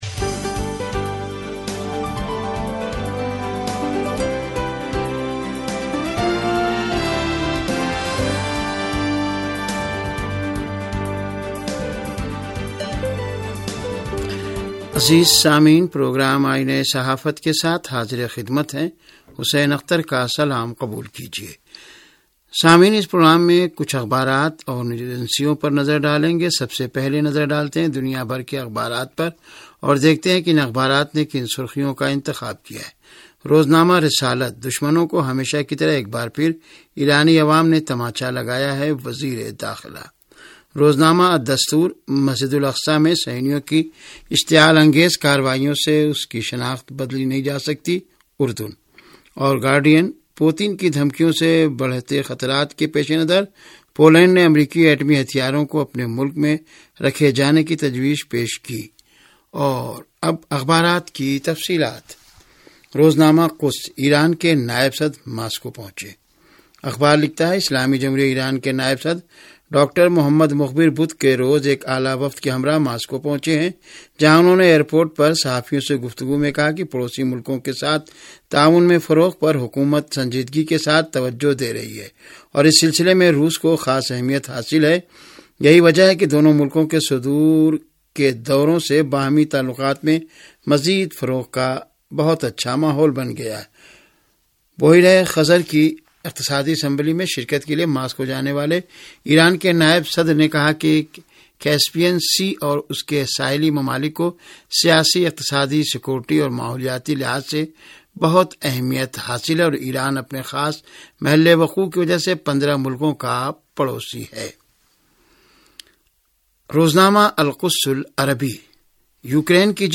ریڈیو تہران کا اخبارات کے جائزے پرمبنی پروگرام - آئینہ صحافت